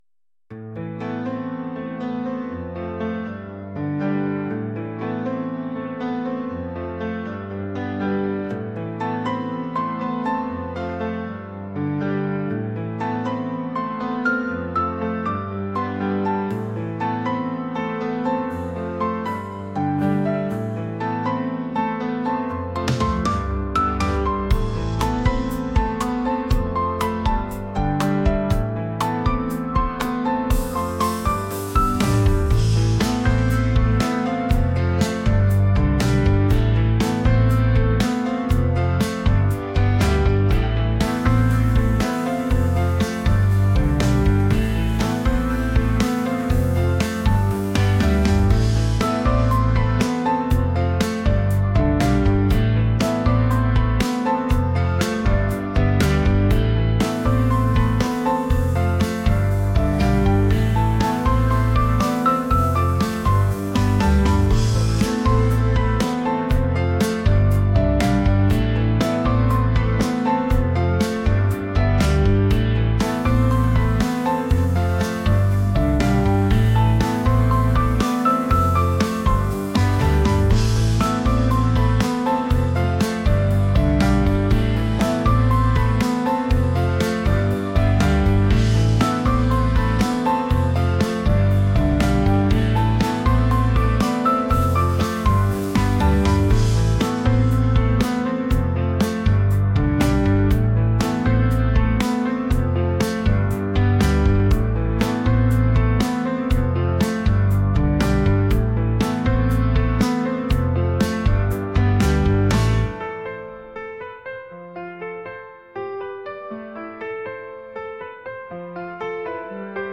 「悲しい」